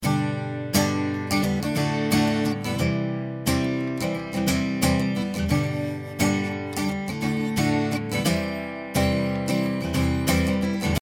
ローカットを例に示してみます。まずはバイパス。